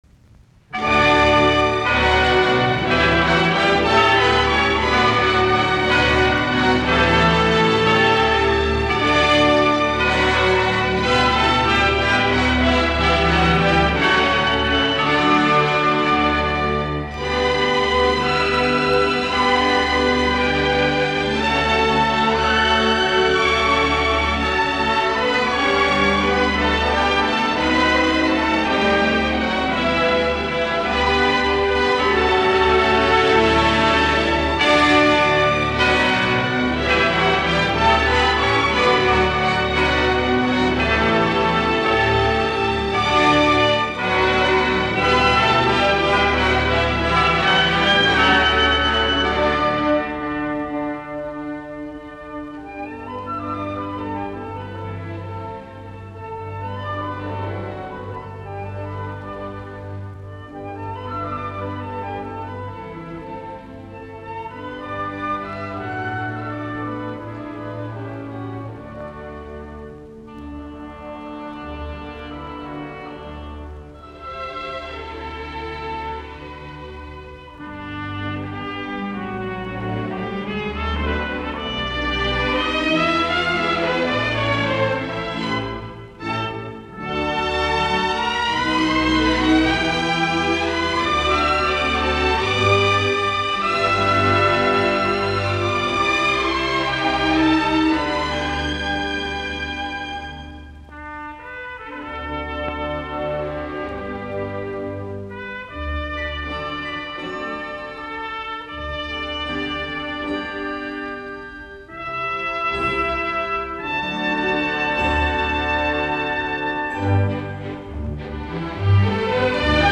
musiikkiäänite
Soitinnus: Ork.